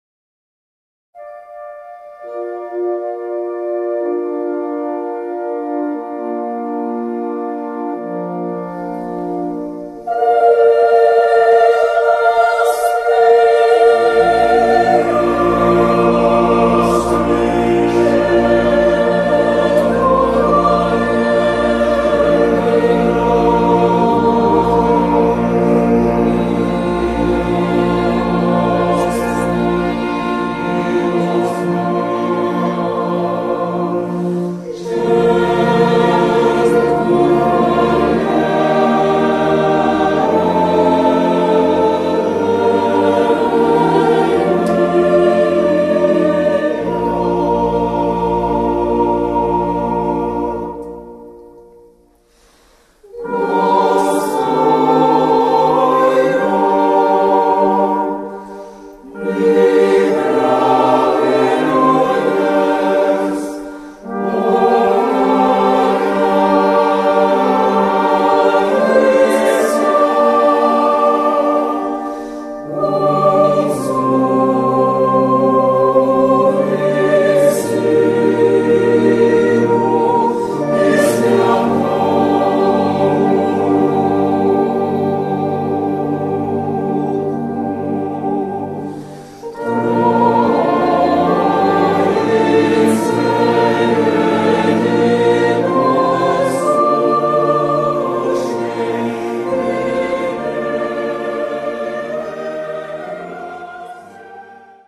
Voicing: Chorus